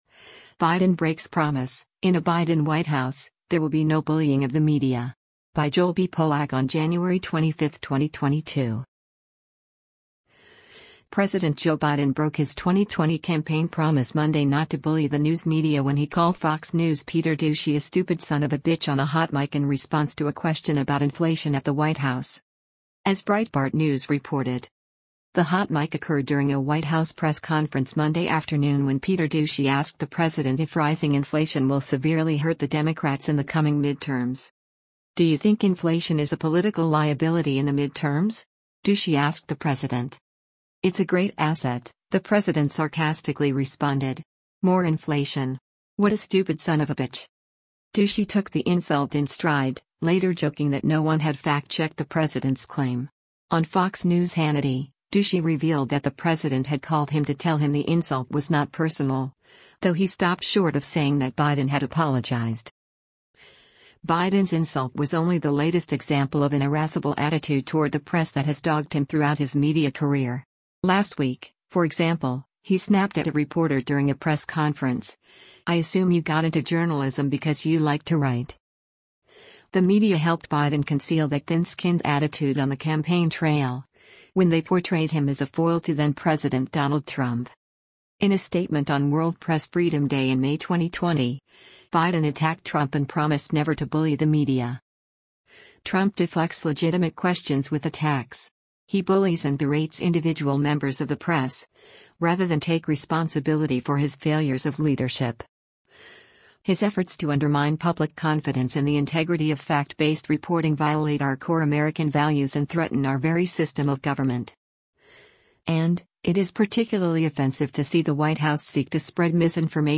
The hot mic occurred during a White House press conference Monday afternoon when Peter Doocy asked the president if rising inflation will severely hurt the Democrats in the coming mid-terms.
“It’s a great asset,” the president sarcastically responded.